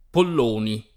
Polloni [poll0ni o
poll1ni secondo i casi] cogn. — possibile una derivaz. da pollone, con -o- chiuso, o da Apollonio, con -o- aperto (ma con eventuale chiusura per attraz. degli accr. in -one) — in tutti i modi, domin. oggi in Tosc. e regioni confinanti una pronunzia chiusa